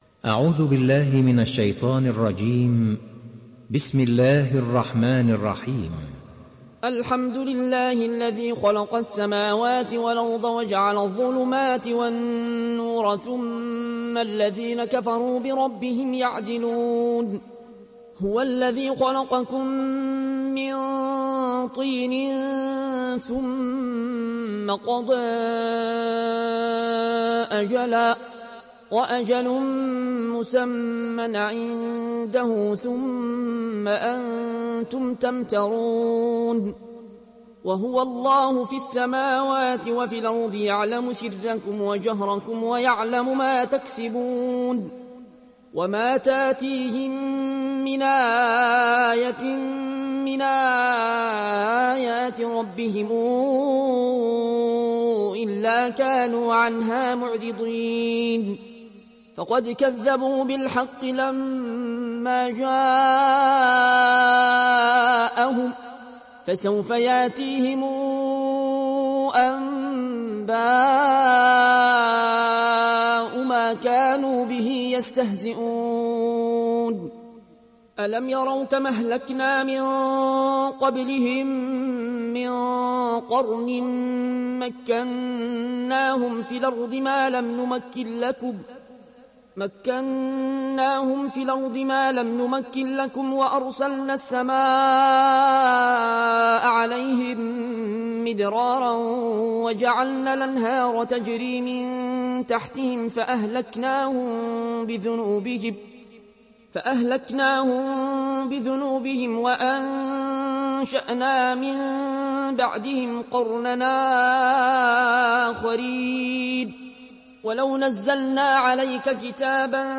Sourate Al Anaam Télécharger mp3 Al Ayoune Al Koshi Riwayat Warch an Nafi, Téléchargez le Coran et écoutez les liens directs complets mp3